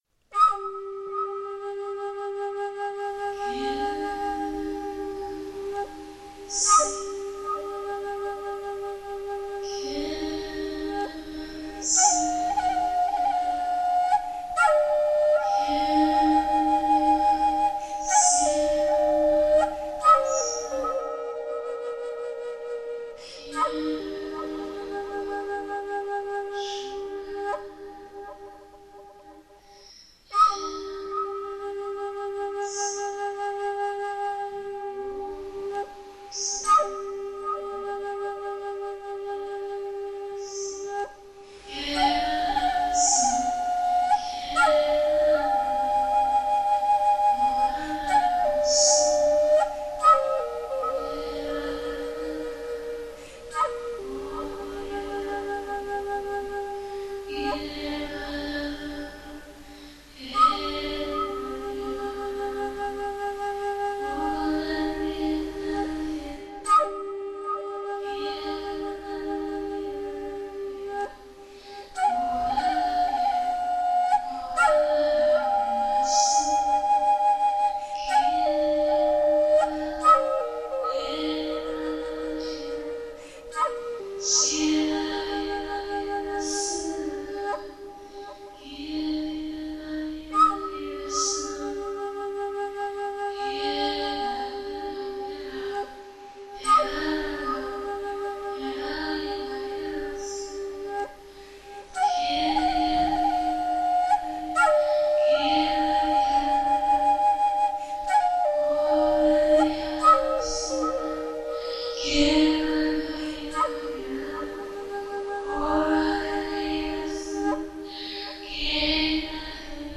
Beiträge mit dem Schlagwort: Sprachengesang
Diese Aufnahme ist gut für „Soaking“-Zeiten.
freedom-in-the-holy-spirit-soaking.mp3